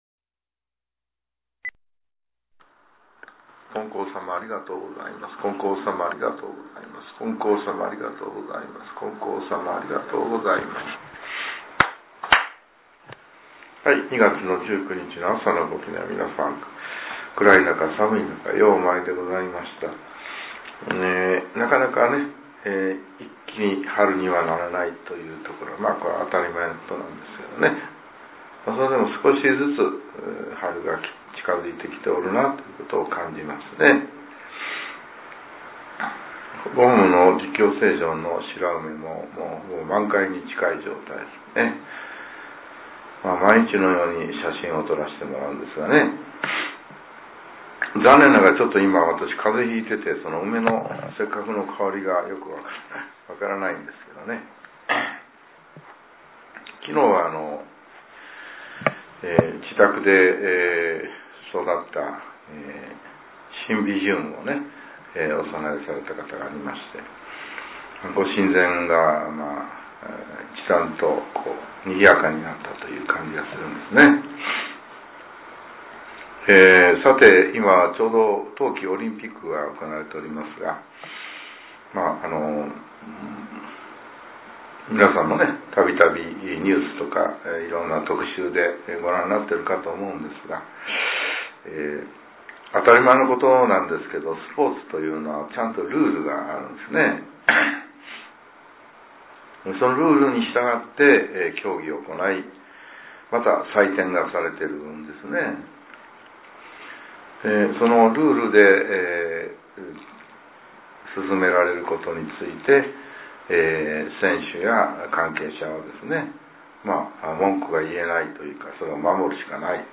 令和８年２月１９日（朝）のお話が、音声ブログとして更新させれています。